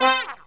SYNTH GENERAL-4 0008.wav